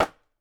Golpe de piedra contra una calabaza
golpe
Sonidos: Acciones humanas